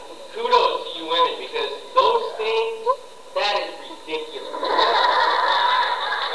Gatecon 2003